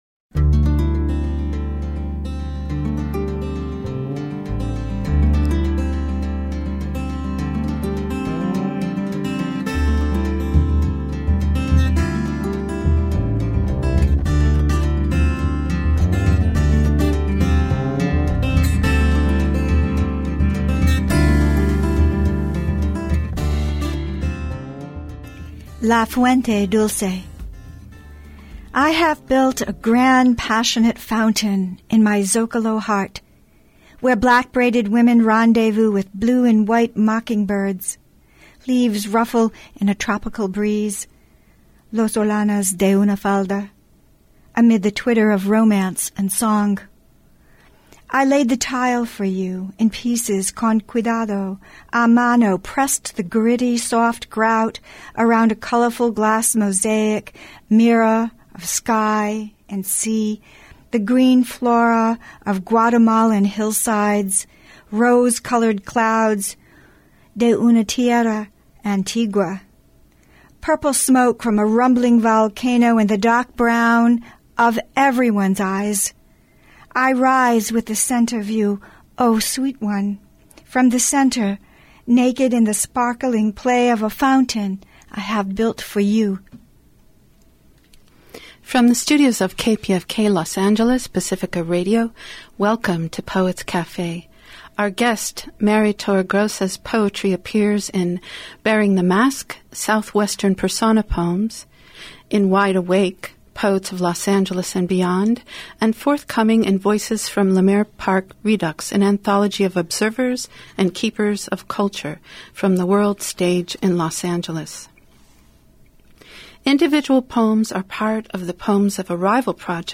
The following interview
originally aired on KPFK Los Angeles (reproduced with permission).